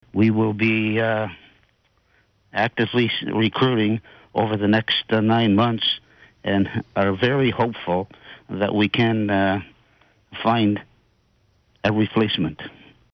Former Deputy Mayor of Brighton Roger McMurray read some of the doctors’ letter to the MIX Morning Crew this morning (Wednesday).